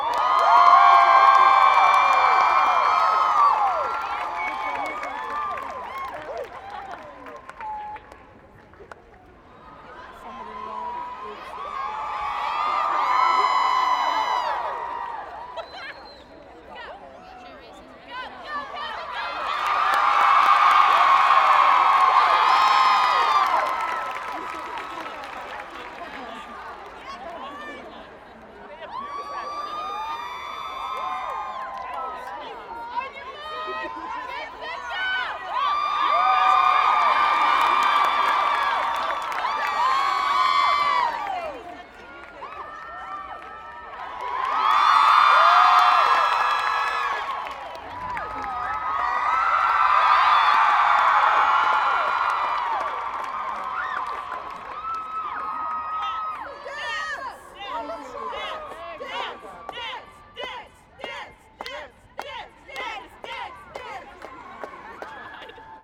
cheer2.wav